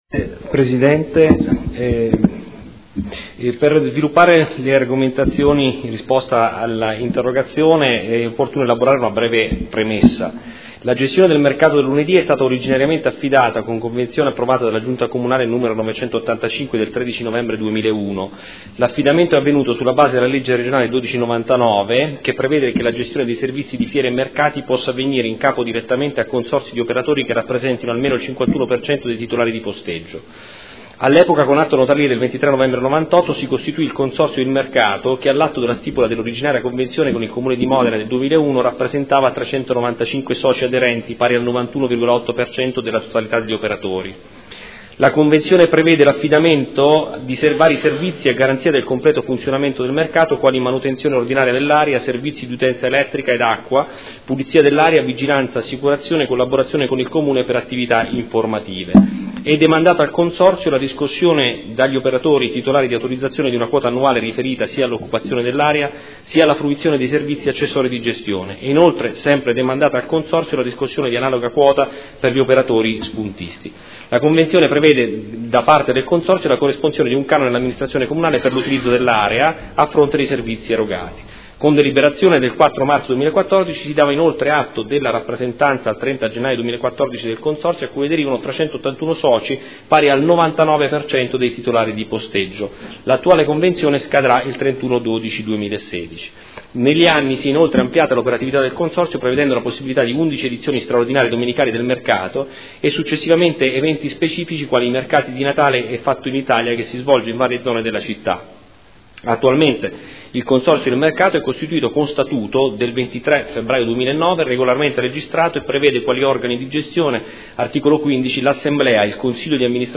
Tommaso Rotella — Sito Audio Consiglio Comunale